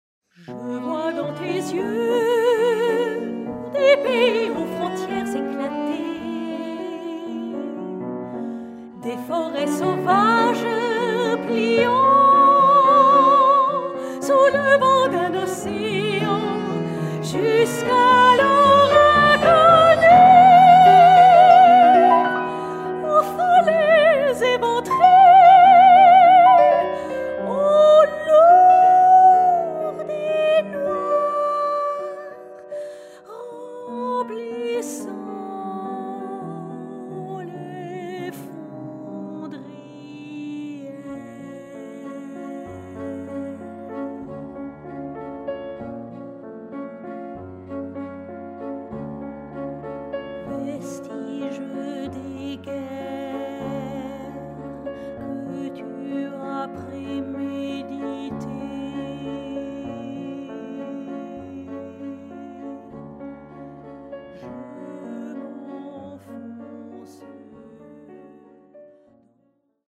chant (soprano)
saxophone(s)
piano, chant, arrangement
alto, accordéon,arrangement